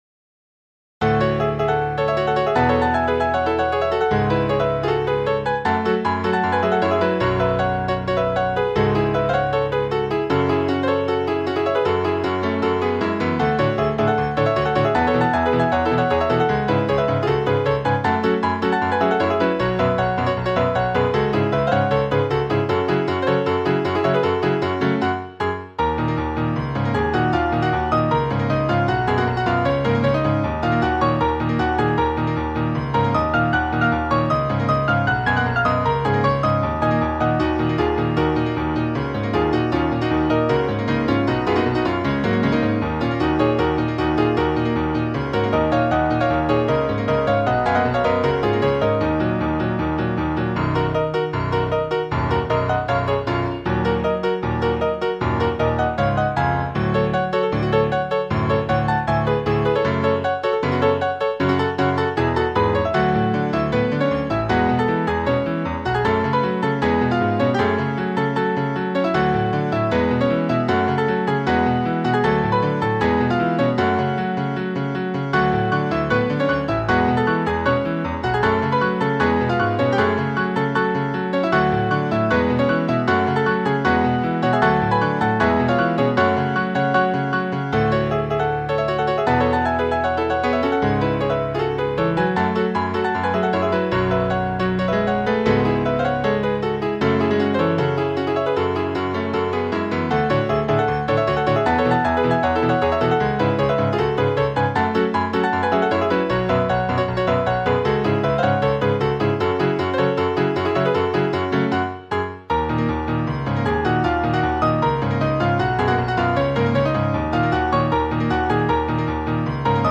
耳コピ作品